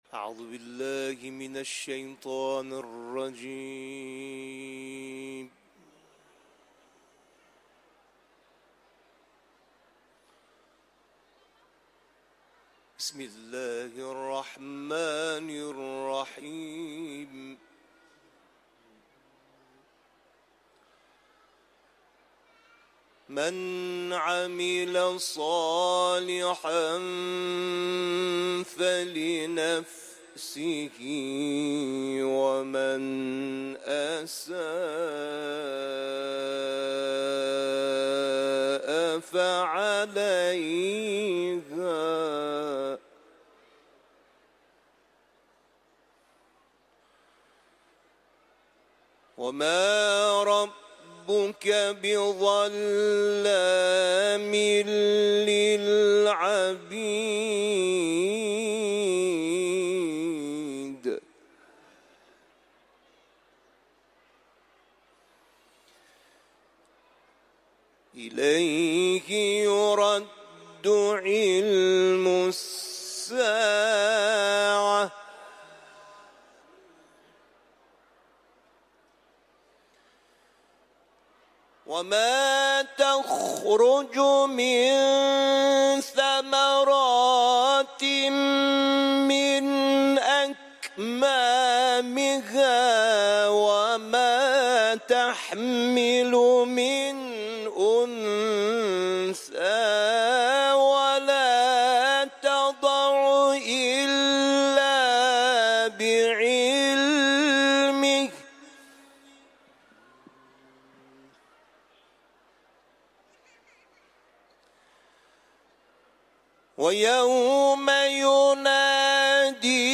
Etiketler: Kuran tilaveti ، Fussilet suresi ، İranlı kâri